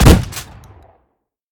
pump-shot-1.ogg